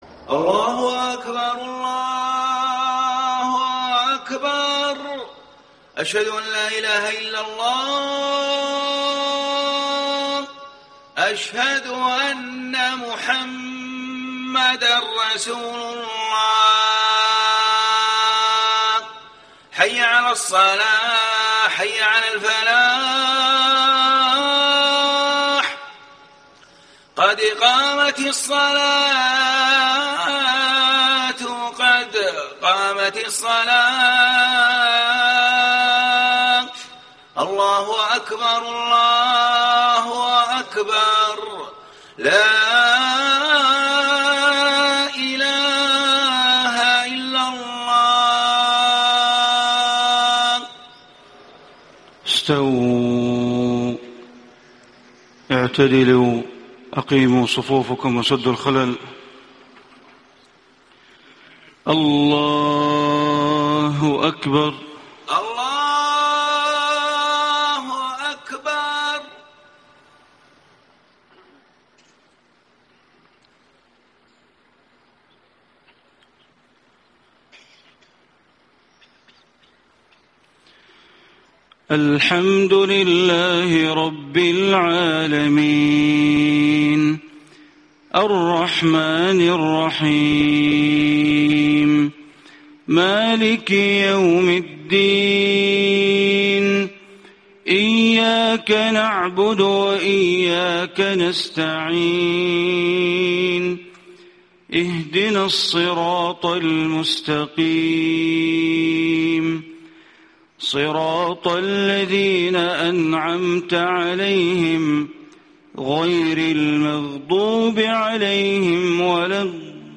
صلاة الفجر 8-2-1435 من سورة يوسف > 1435 🕋 > الفروض - تلاوات الحرمين